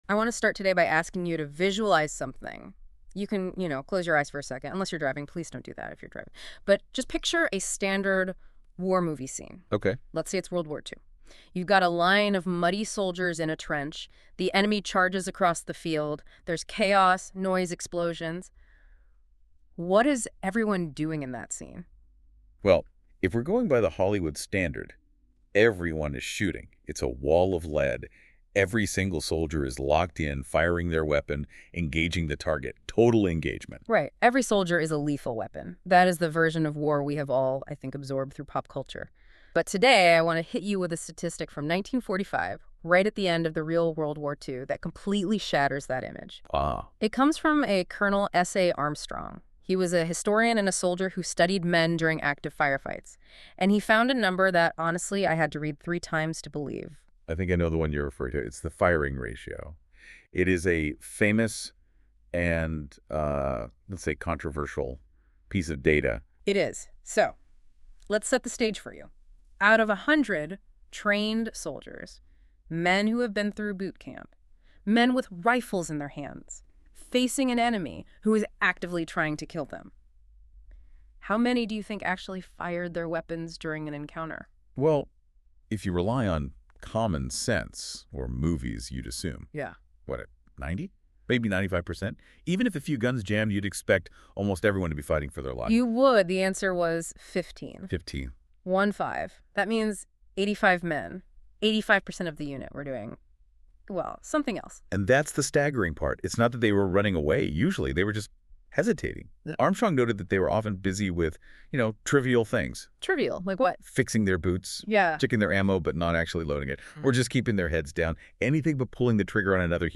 Understanding War: A Philosophical Inquiry (1989) | John McMurtry | Science for Peace | NotebookLM - TOWARDS LIFE-KNOWLEDGE